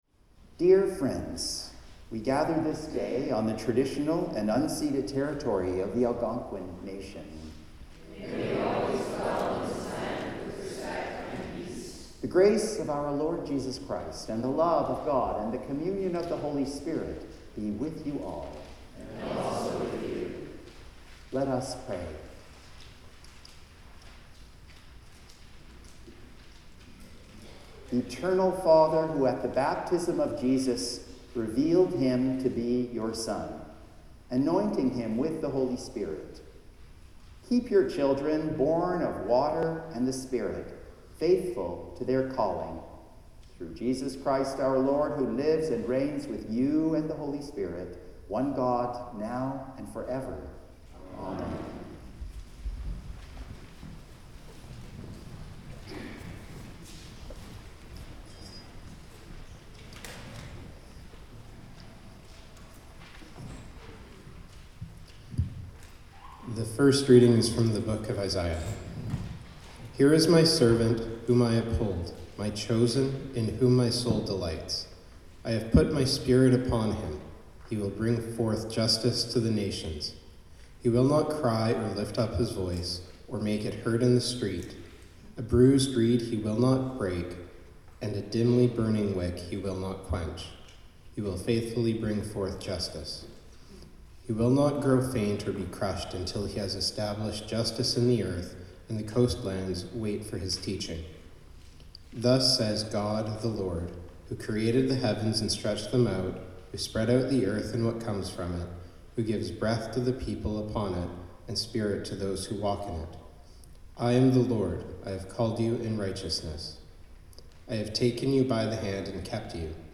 Hymn 649: Breathe on me, Breath of God
The Lord’s Prayer (sung)
Hymn 424: Sing Praise to God